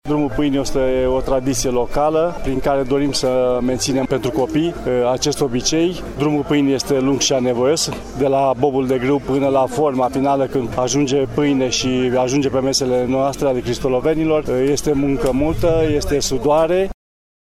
Primarul Cristianului, Gigu Cojocaru: